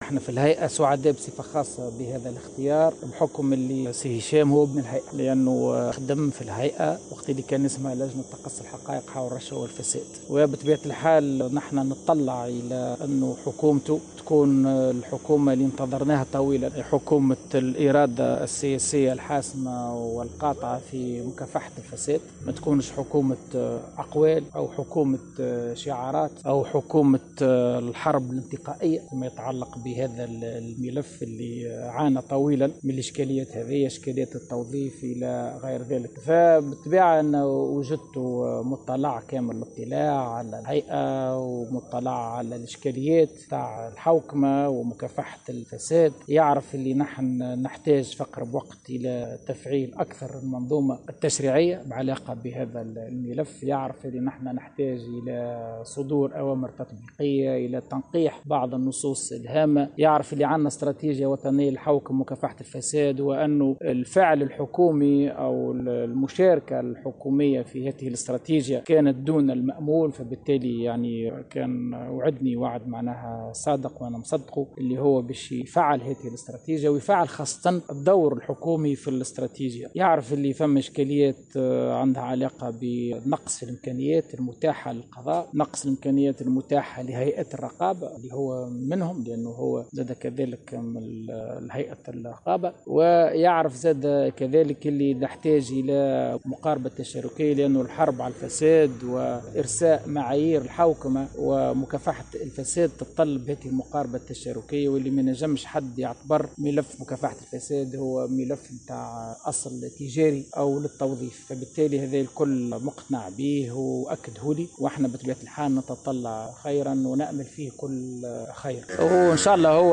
وقال شوقي الطبيب، في تصريح إعلامي عقب لقائه برئيس الحكومة المكلف هشام المشيشي مساء اليوم الخميس، إن الهيئة تتطلع إلى أن تكون حكومة هشام المشيشي المرتقبة حكومة الإرادة السياسية الحاسمة في مكافحة الفساد ولا تكون حكومة شعارات أو حكومة "حرب انتقائية"، وفق توصيفه، فيما يتعلق بهذا الملف.